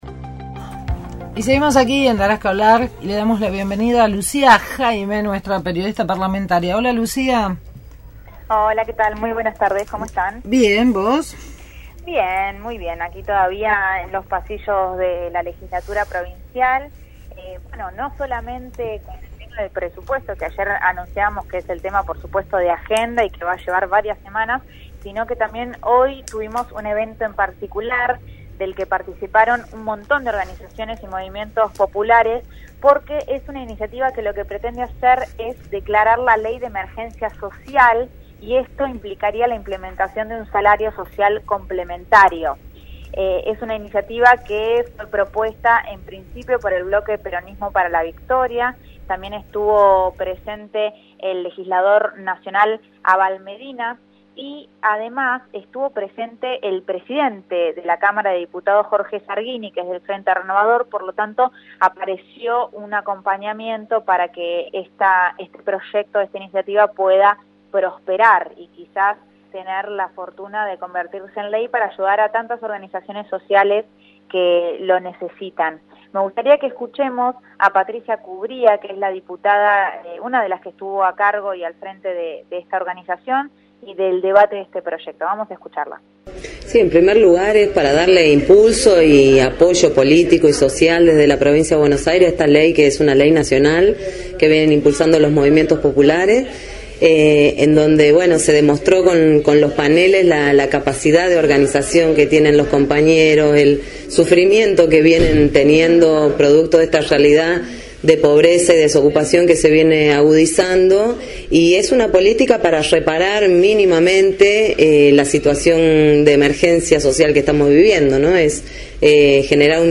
Testimonios del senador nacional, Juan Manuel Abal Medina y de la Diputada Bonaerense, Patricia Cubría.